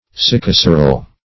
Search Result for " sycoceryl" : The Collaborative International Dictionary of English v.0.48: Sycoceryl \Syc`o*ce"ryl\, n. [Gr.